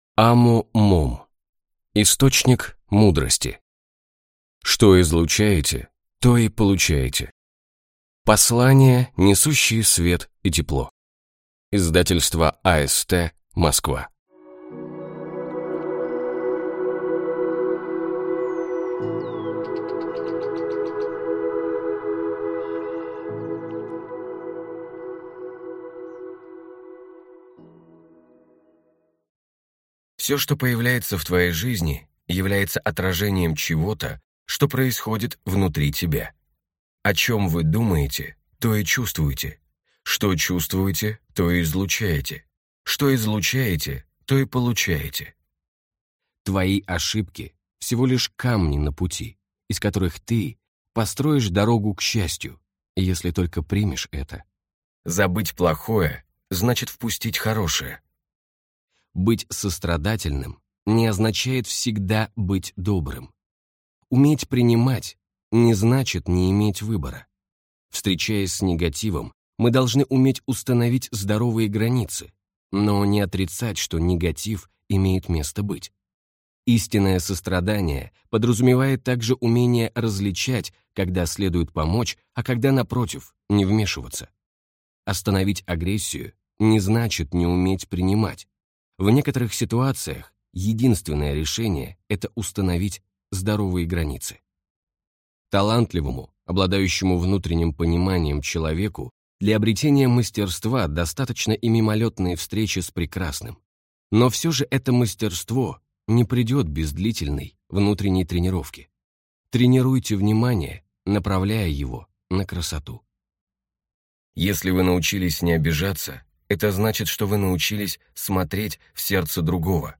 Аудиокнига Источник мудрости. Что излучаете, то и получаете. Послания, несущие Свет и Тепло | Библиотека аудиокниг